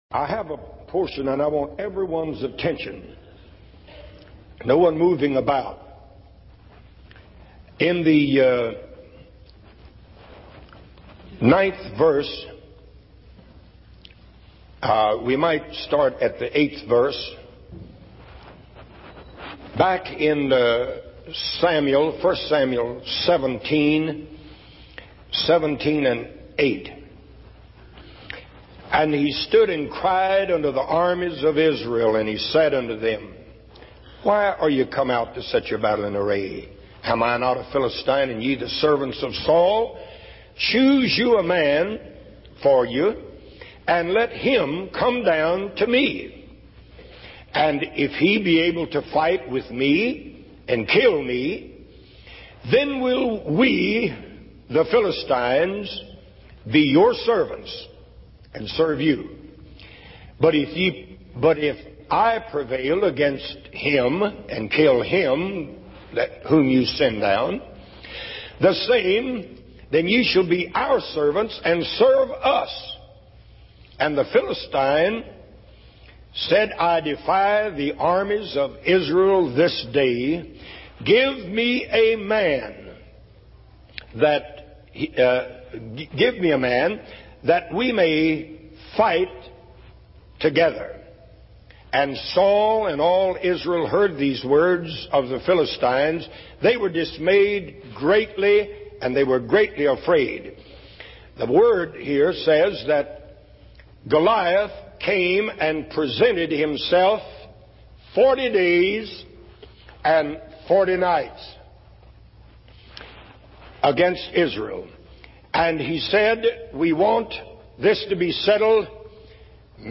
In this sermon, the preacher talks about the story of David and Goliath from the Bible. He emphasizes the importance of trusting in God's supernatural power to overcome our giants.